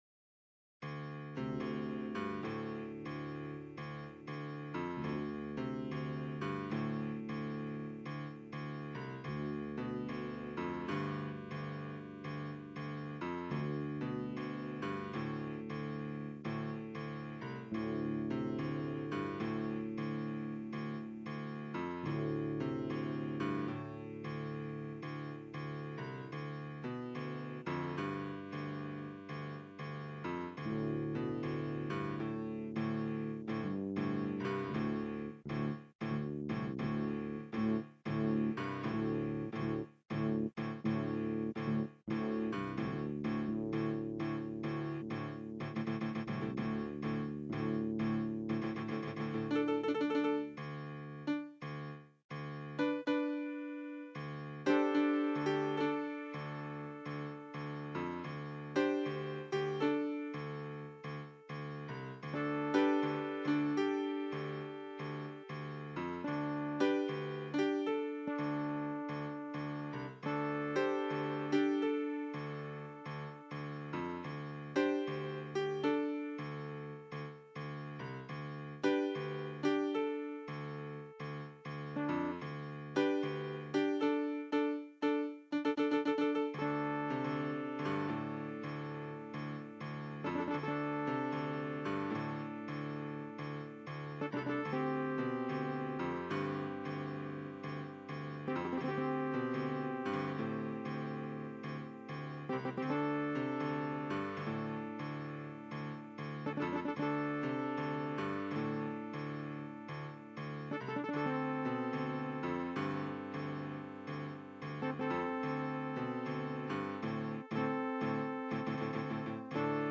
Dead end town Alley bgm music for video game.